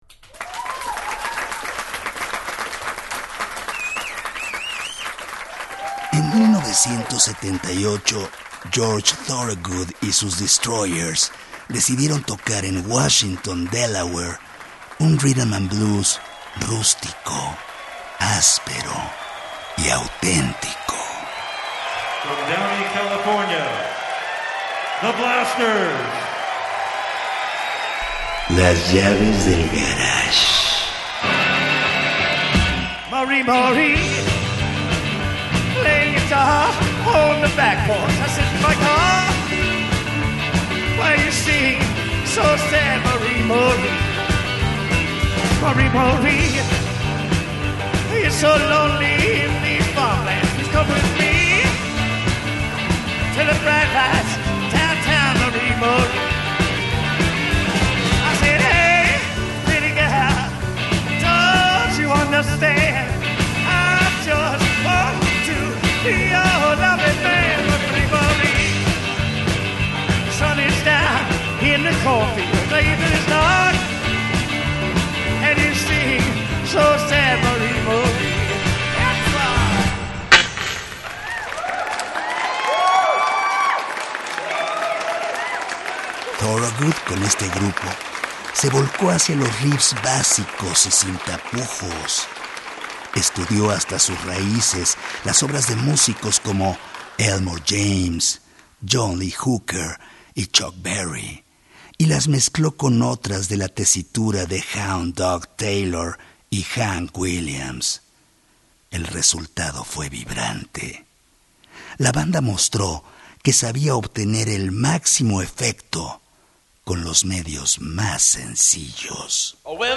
The Knack es un grupo representativo del power pop, corriente que se convirtió en una vena importante para el rock de garage en los 80’s.
Suscríbete al Podcast con También puedes DESCARGAR el programa DESDE AQUI Escucha un nuevo capítulo de Las Llaves del Garage todos los lunes a las 23:30 horas en el 1060 de AM, Radio Educación , Ciudad de México.